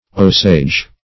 Osage \Osage\ prop. n.